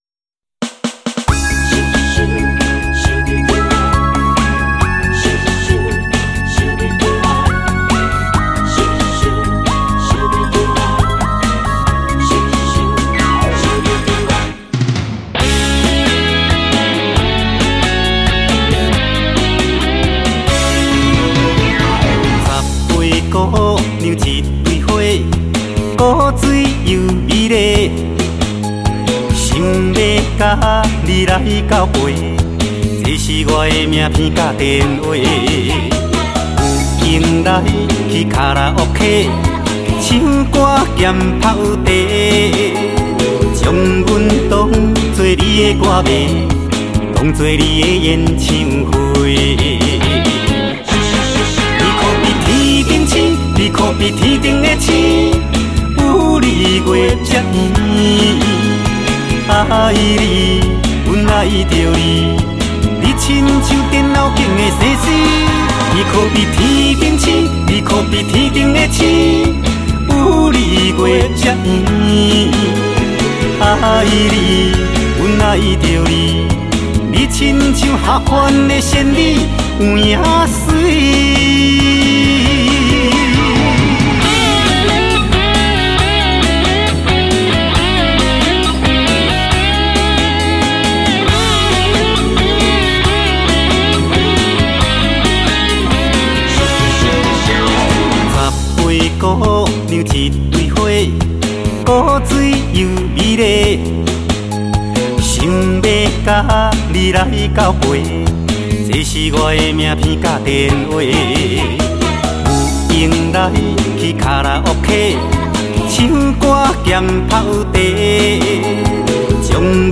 恰恰舞曲风 热情又轻松